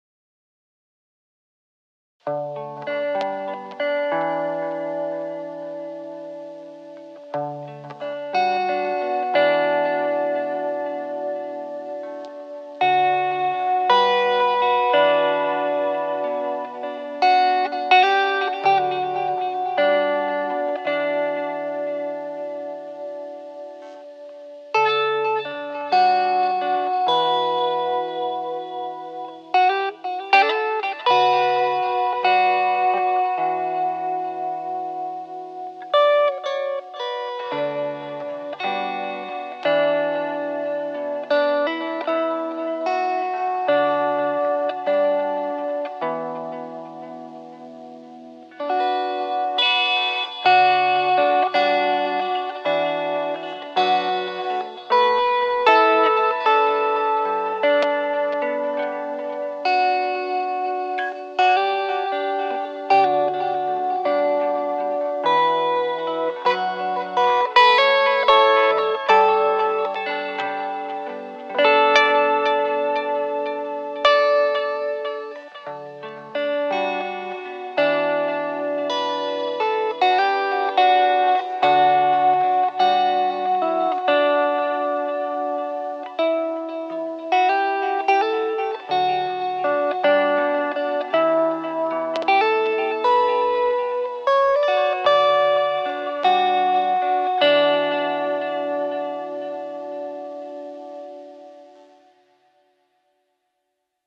It's a somber track about loss and reflection.